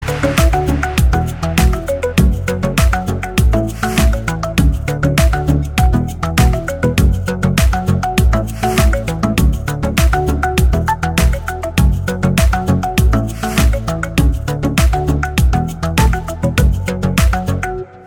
chillout
Eurodance
танцевальная музыка